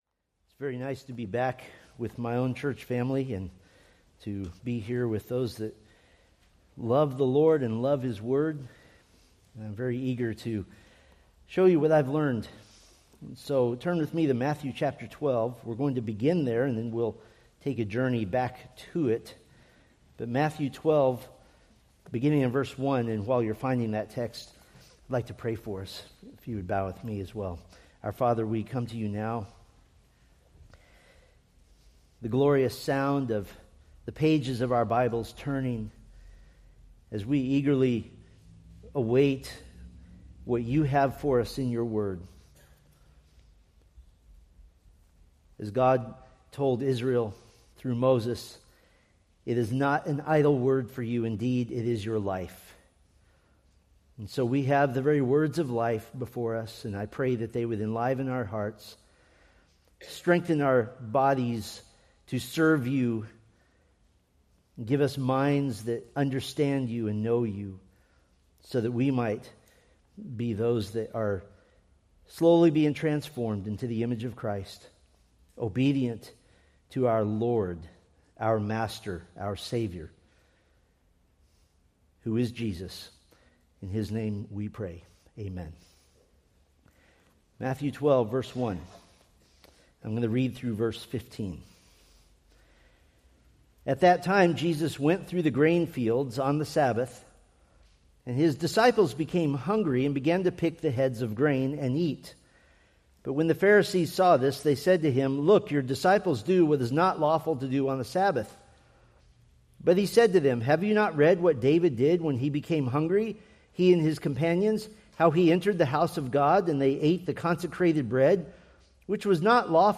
Preached November 2, 2025 from Matthew 12:1-14